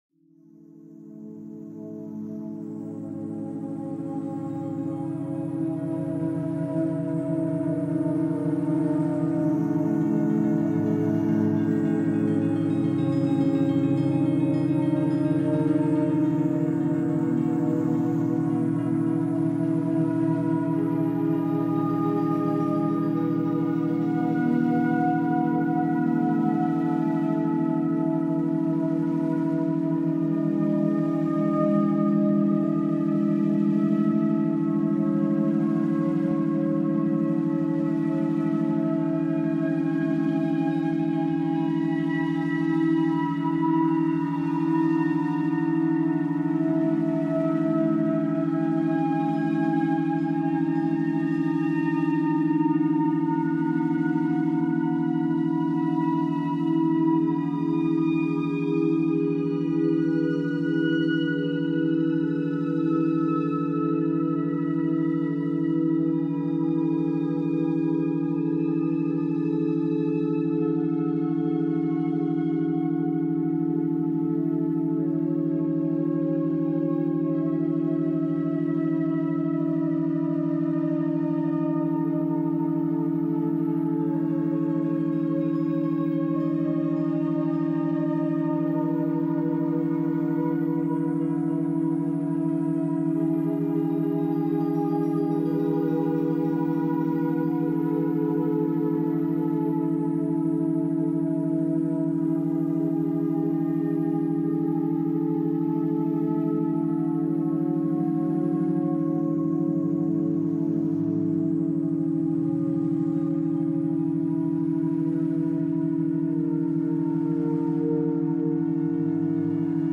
Régénération : Fréquences Apaisantes
Aucun bruit parasite, aucune coupure soudaine.